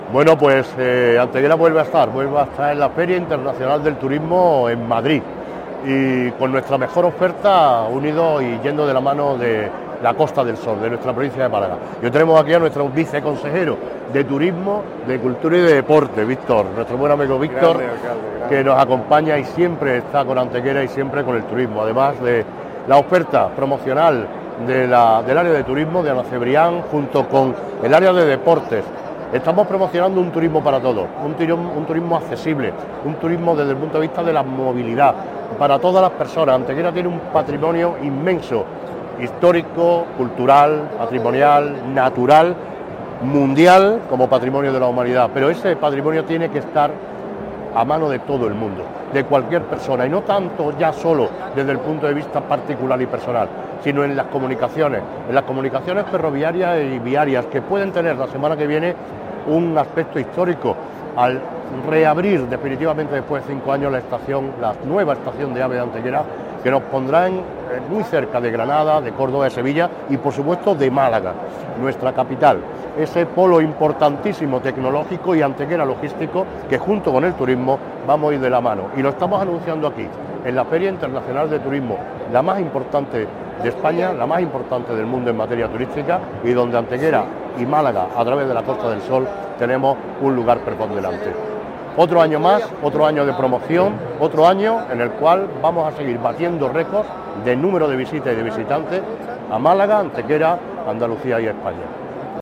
El alcalde de Antequera, Manolo Barón, el teniente de alcalde delegado de Deportes, Juan Rosas, y la teniente de alcalde de Turismo, Ana Cebrián, encabezan la presencia institucional del Ayuntamiento de Antequera en FITUR 2023, Feria Internacional del Turismo que se celebra en Madrid desde hoy hasta el próximo domingo.
Cortes de voz